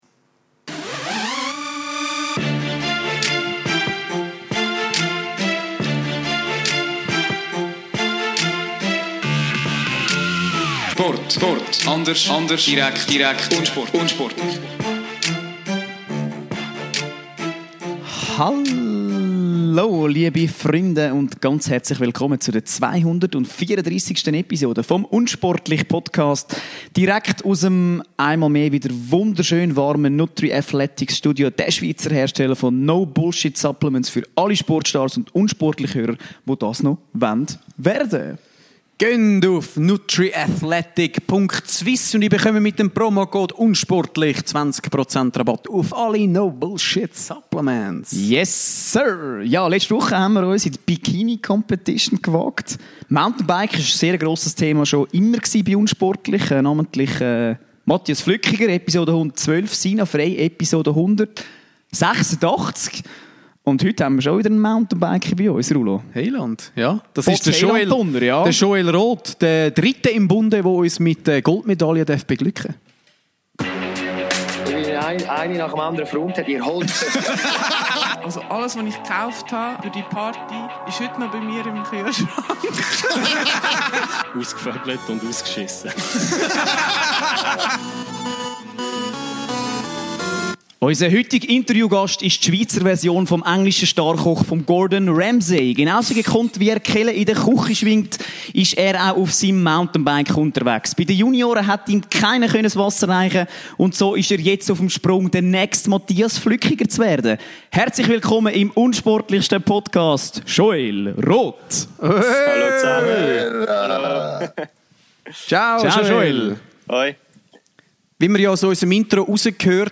Grandios auf zwei Rädern, mindestens genauso super im Interview!